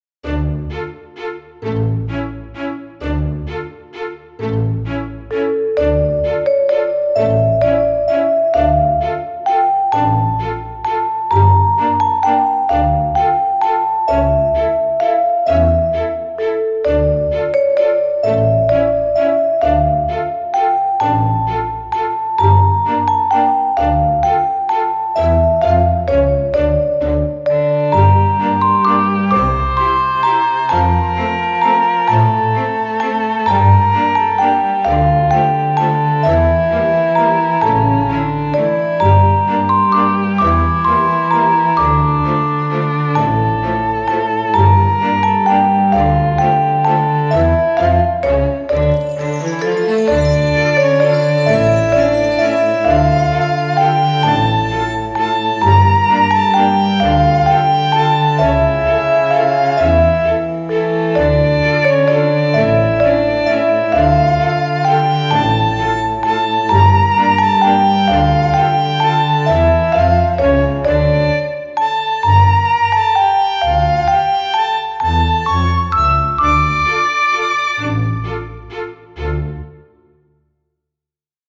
テンポ：♩=130
主な使用楽器：チェレスタ、ストリングス、グロッケン etc